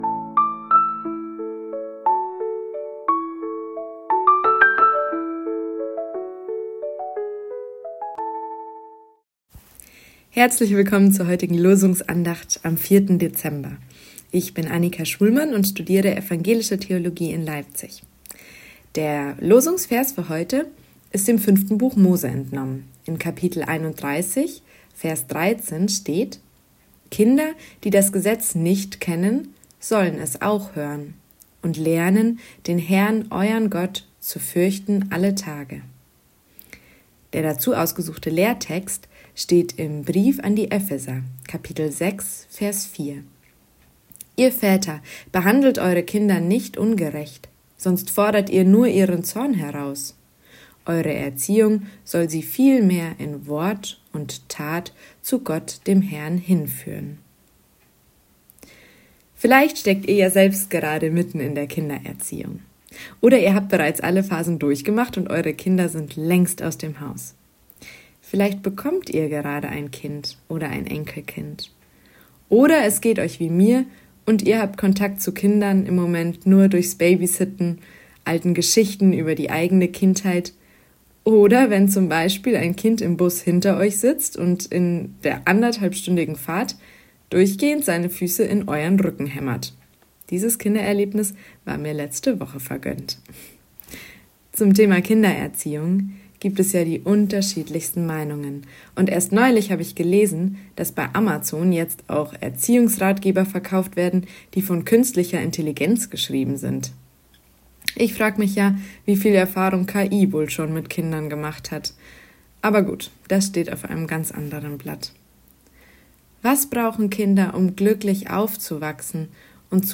Losungsandacht für Donnerstag, 04.12.2025